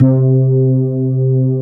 FRETLESSC4-L.wav